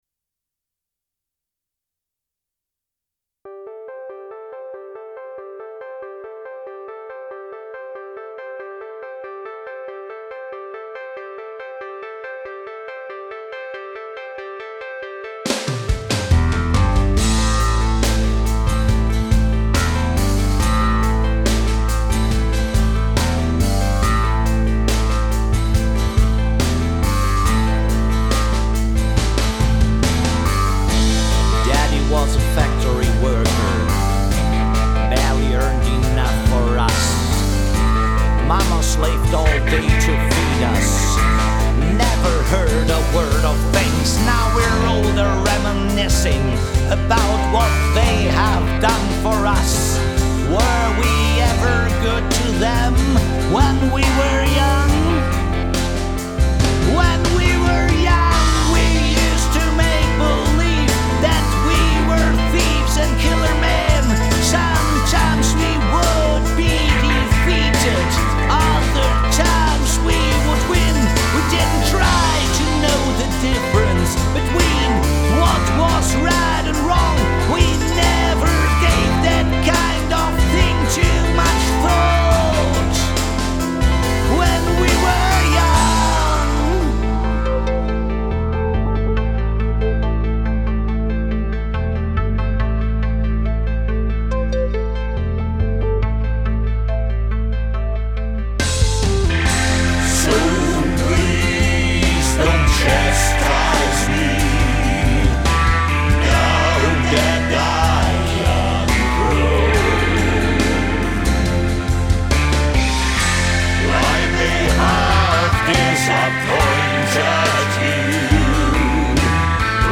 musical versuch